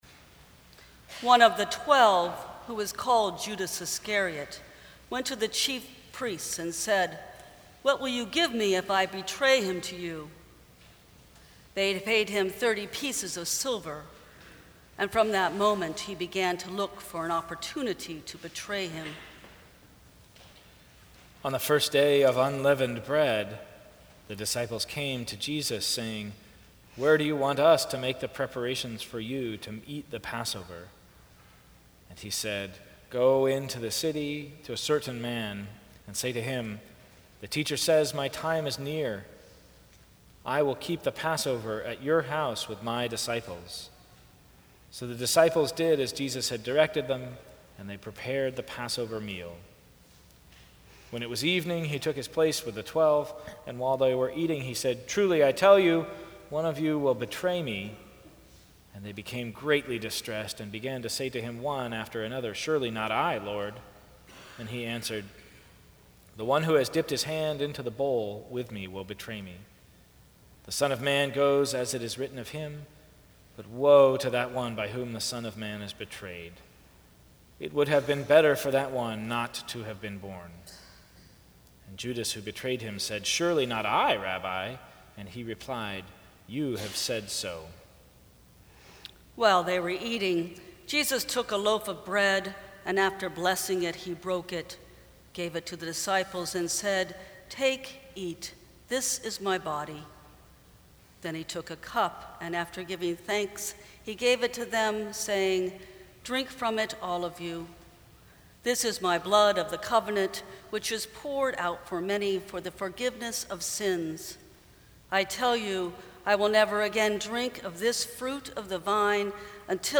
A dramatic Reading of The Passion of Our Lord According to Matthew
Sermons